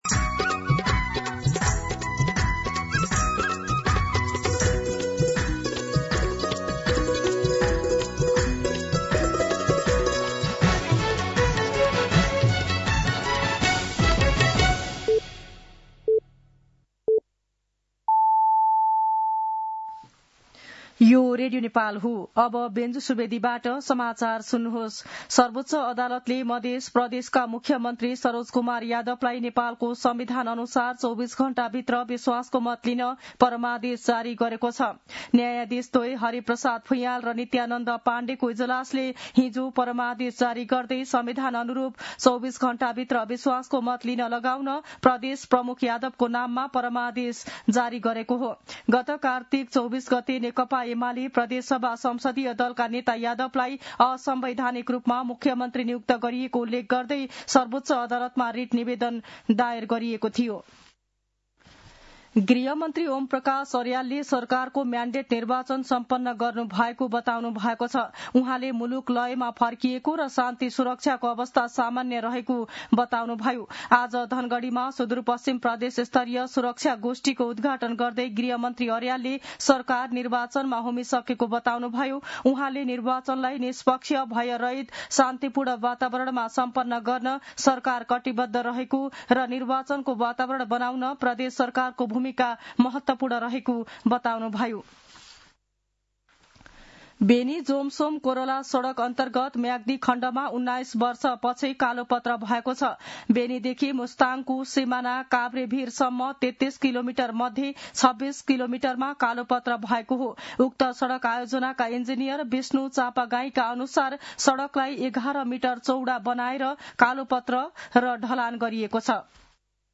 An online outlet of Nepal's national radio broadcaster
मध्यान्ह १२ बजेको नेपाली समाचार : १६ मंसिर , २०८२